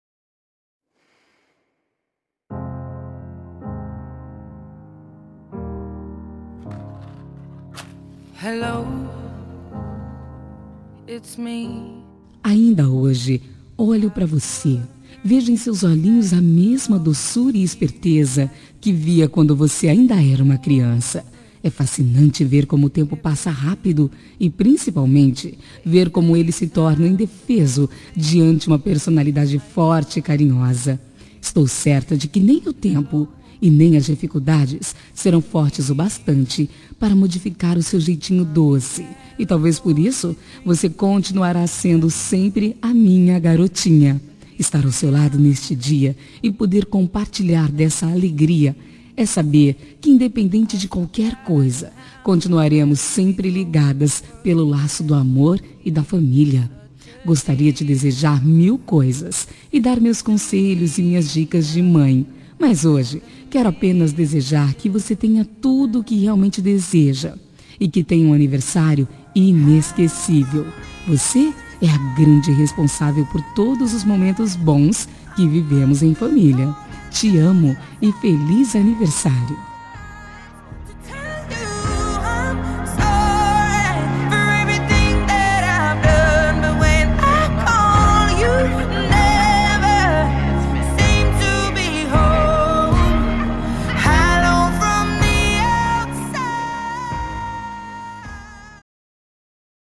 Telemensagem de Aniversário de Filha – Voz Feminina – Cód: 1757